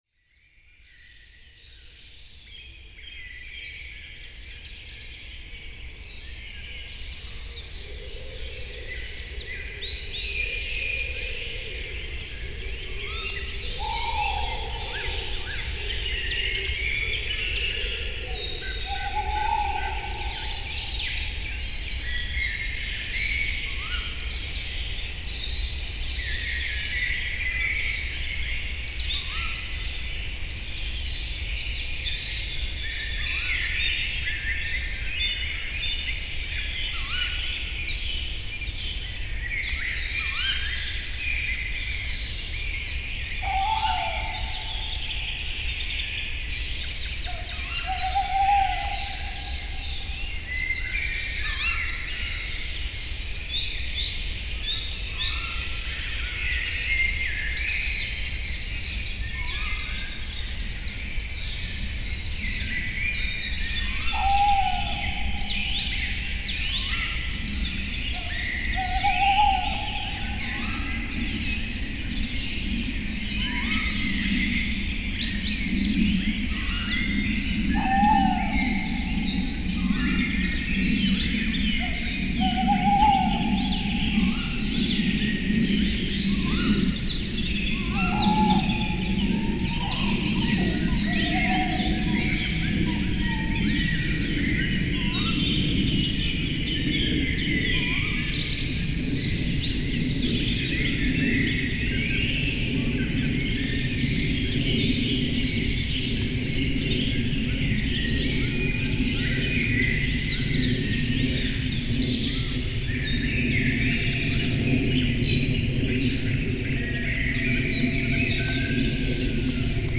PFR07669, 130424, Tawny Owl Strix aluco, male, female Zabelstein, Germany, ORTF, Neumann KM184